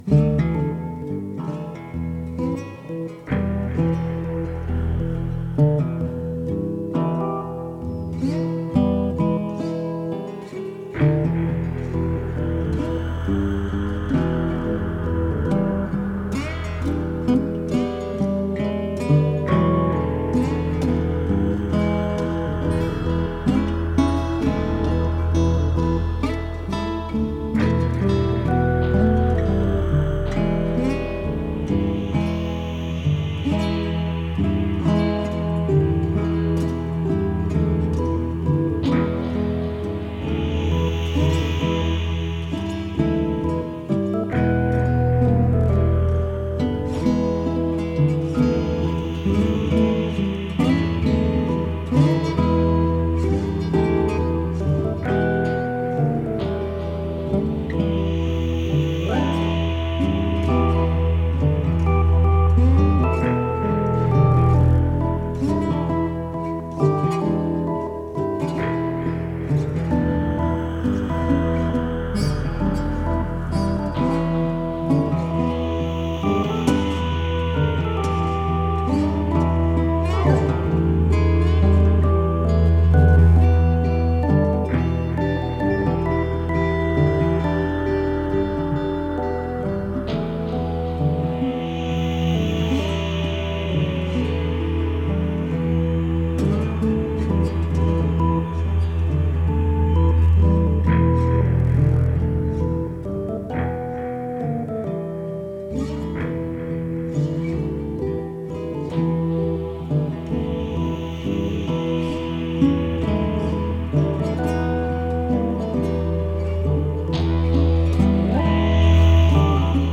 Pusierender Folk, Erzählung. Gitarre und Orgel